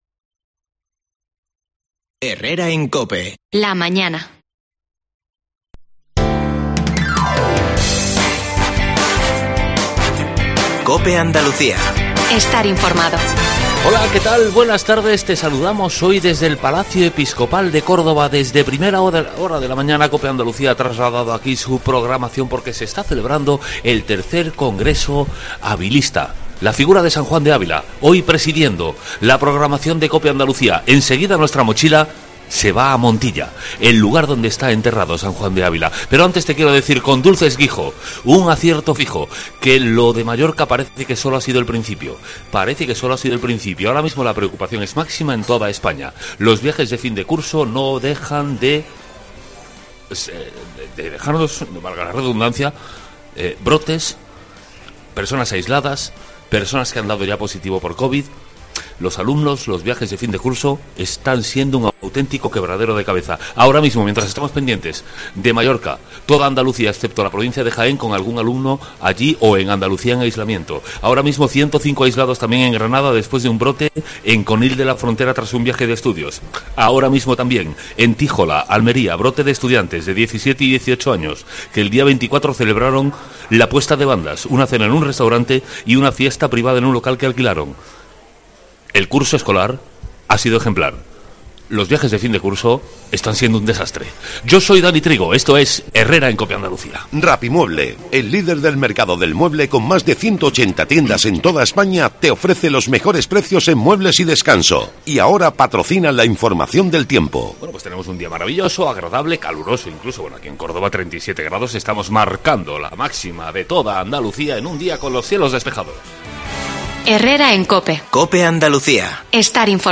Escucha la entrevista completa a Rafael Llamas, alcalde de Montilla